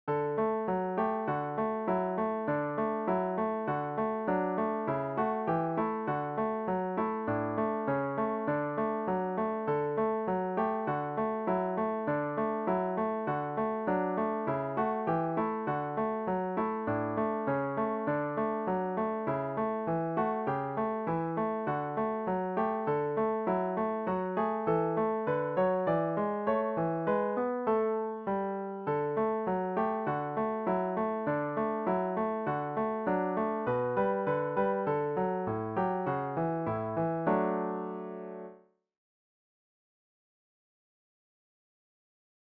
Produced digitally in Finale music notation software